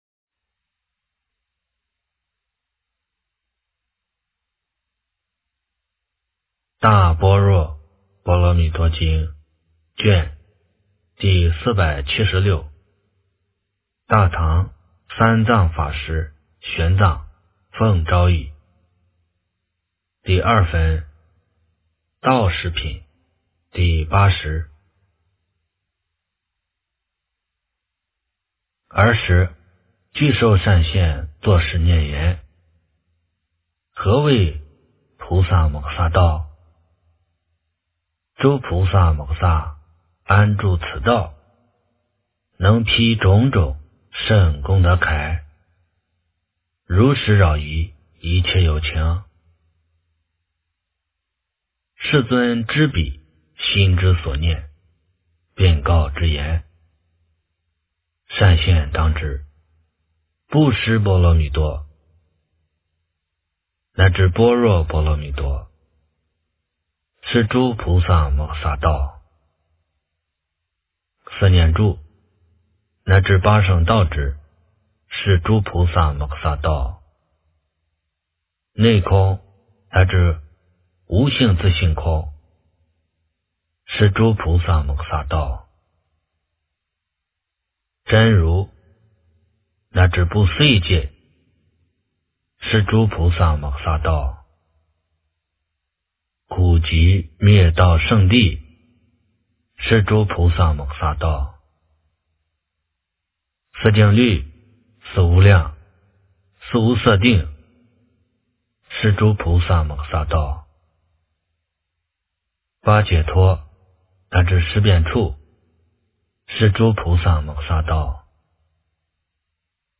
大般若波罗蜜多经第476卷 - 诵经 - 云佛论坛